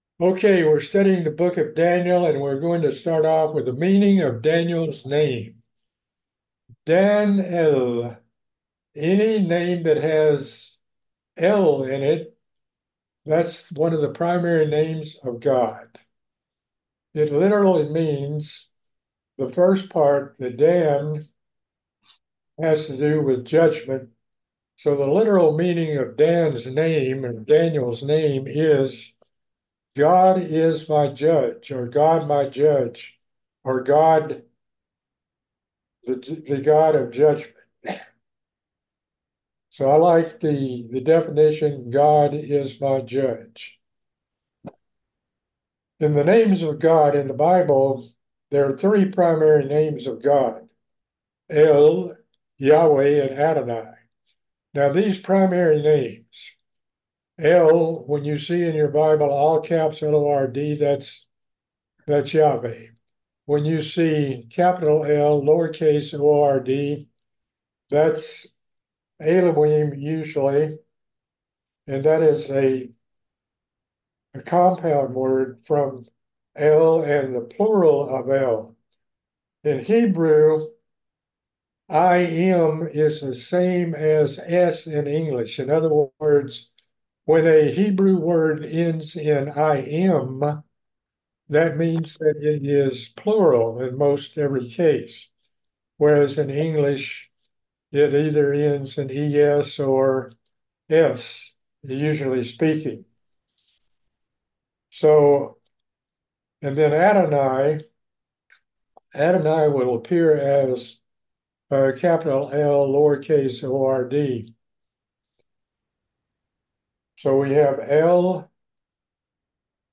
The first is a series of Bible Studies into the book of Daniel.